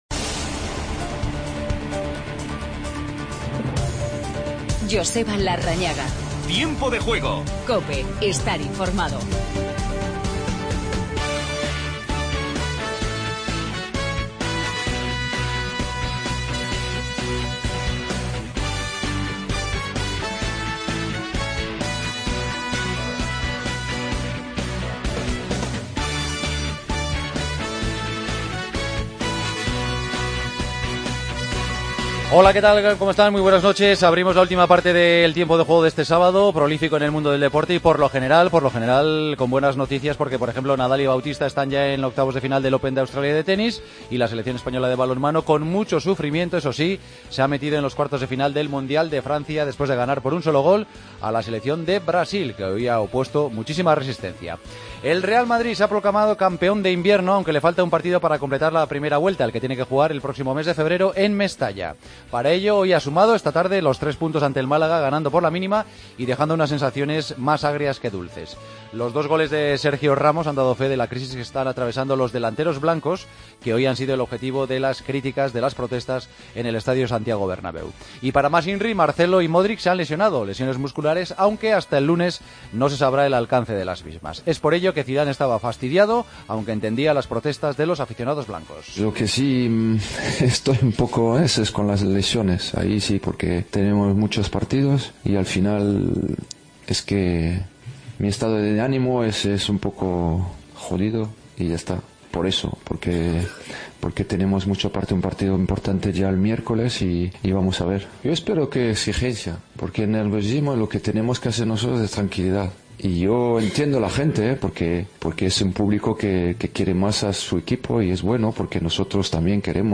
Hablamos con Carlos Soler. Escuchamos a Sergio Ramos.